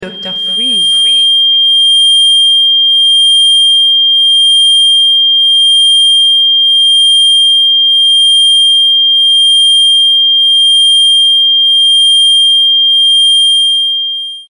La fréquence 3200 Hz est utilisée dans certains protocoles vibratoires pour son impact possible sur la réparation tissulaire, le renouvellement cellulaire et la vitalité globale.